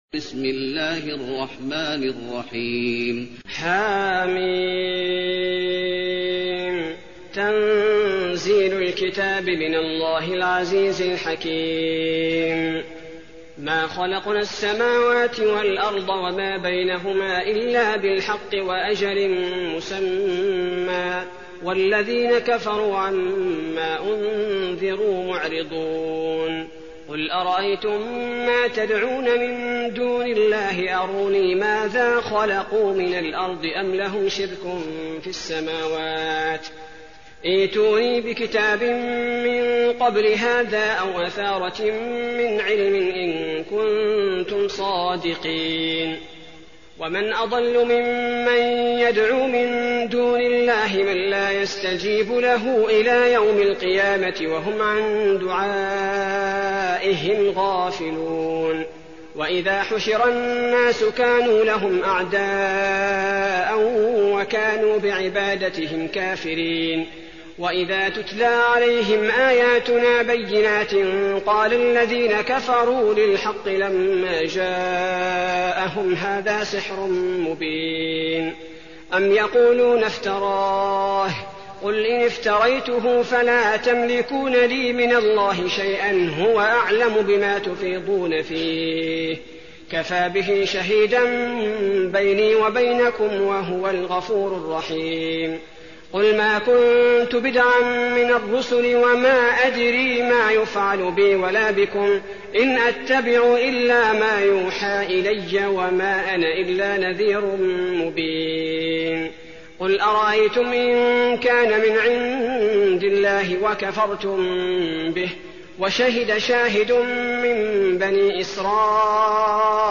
المكان: المسجد النبوي الأحقاف